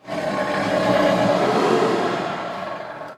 Add some tiger sounds
sounds_tiger_snarl_03.ogg